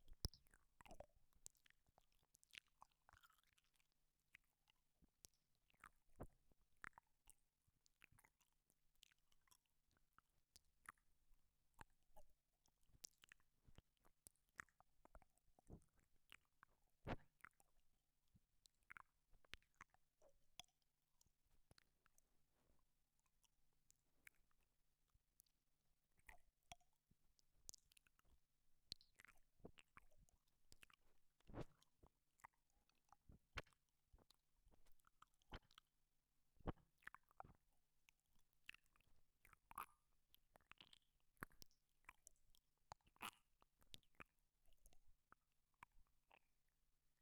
bouche1.wav